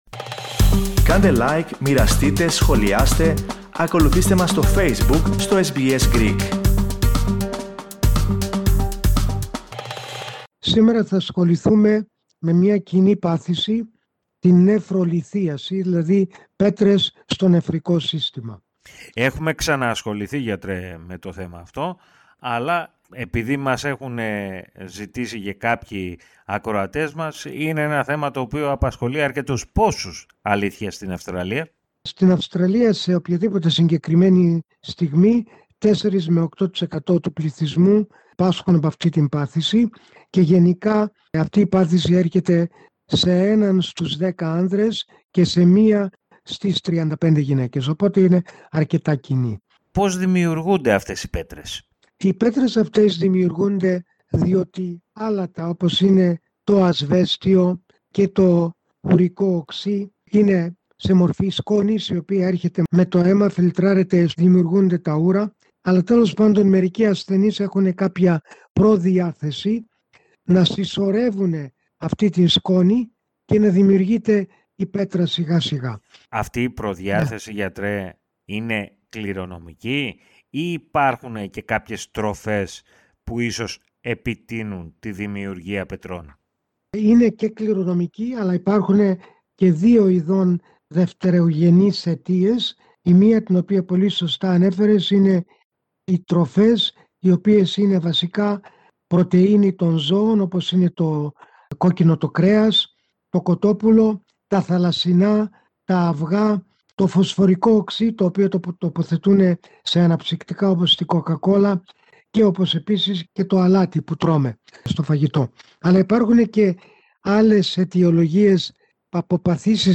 Ακούστε ολόκληρη τη συνέντευξη για τα αίτια της δημιουργίας πετρών στα νεφρά, και για τους τρόπους αντιμετώπισης της πάθησης.